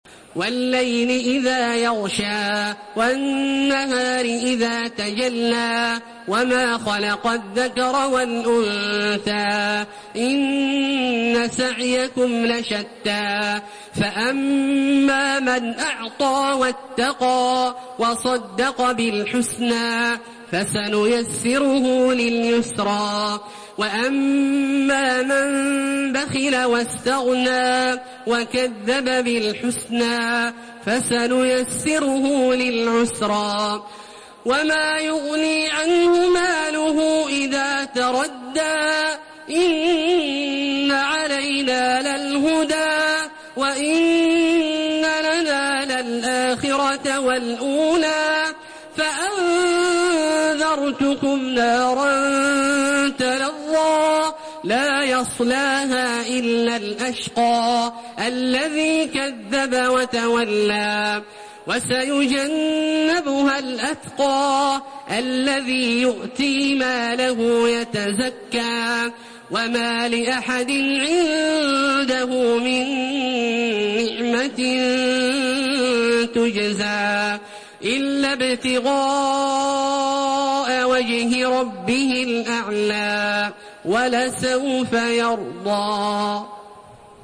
Surah Al-Layl MP3 by Makkah Taraweeh 1432 in Hafs An Asim narration.
Murattal